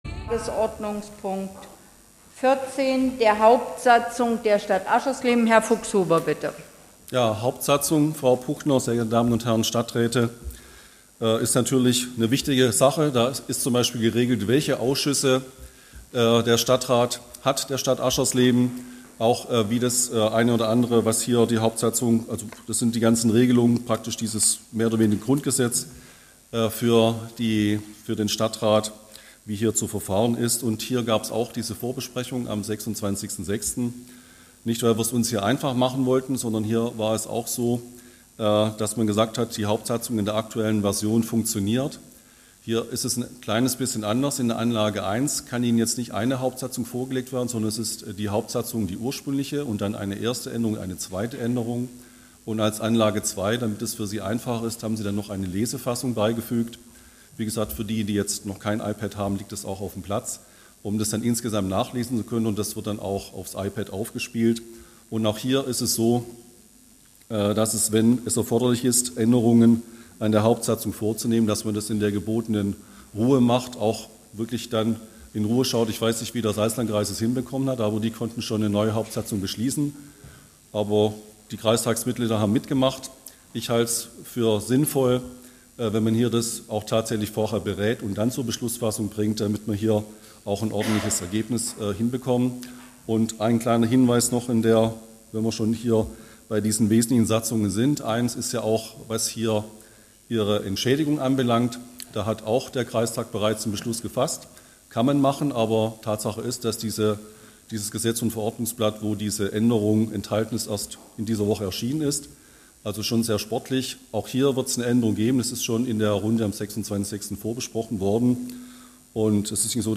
Stadtratssitzung in Aschersleben
radio hbw hat den öffentlichen Teil der Sitzung ab der Erteilung der Sende-Erlaubnis für die neue Legislaturperiode mitgeschnitten.